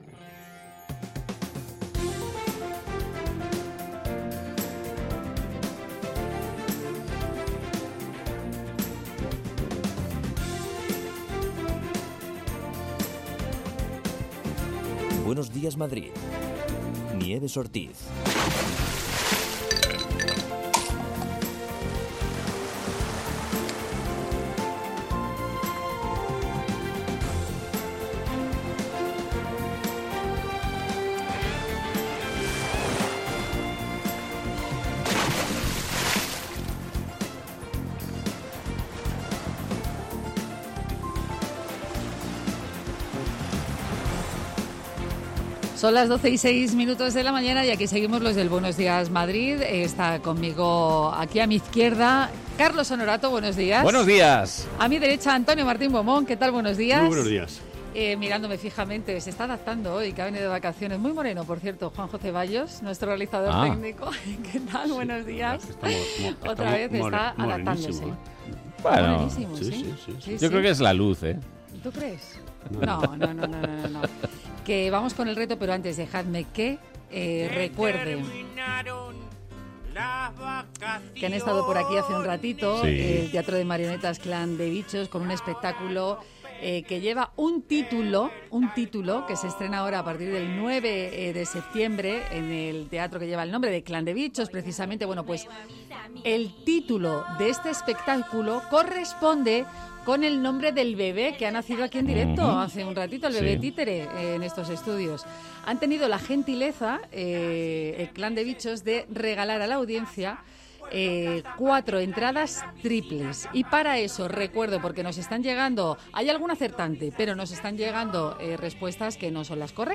Tres horas más de radio donde se habla de psicología, ciencia, cultura, gastronomía, medio ambiente, consumo.